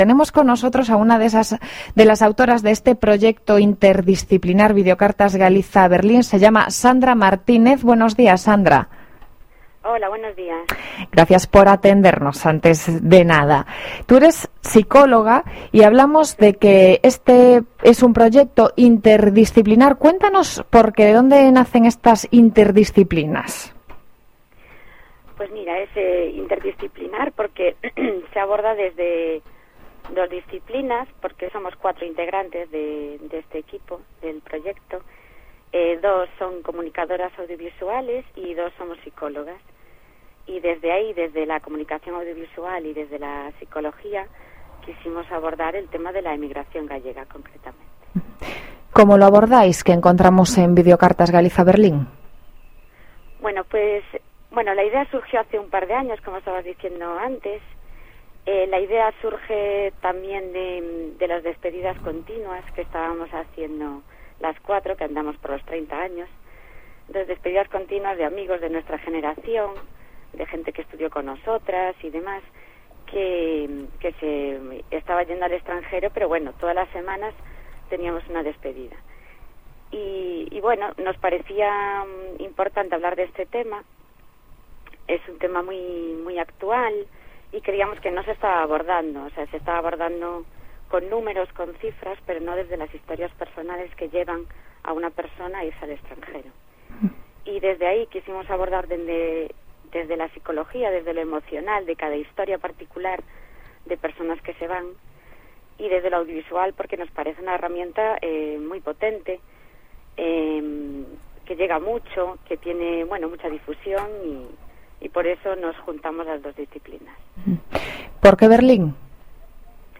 por teléfono